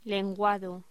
Locución: Lenguado